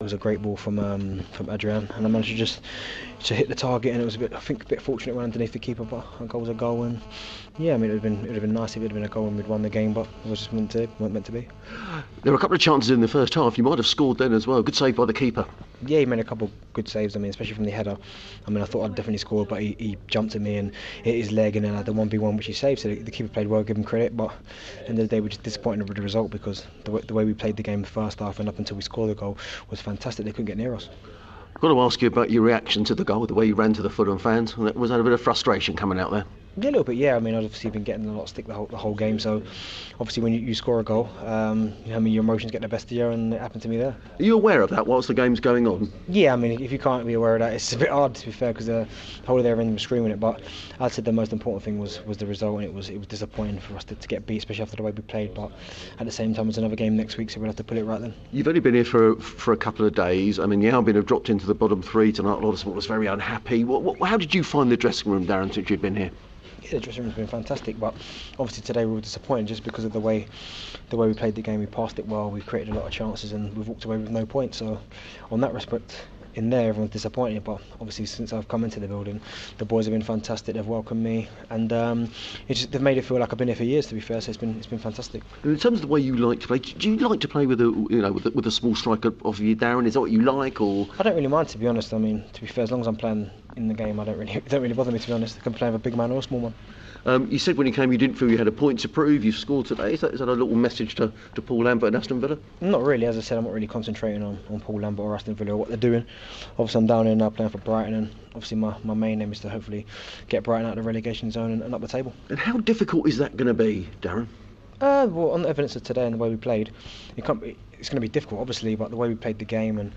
Albion striker Darren Bent speaks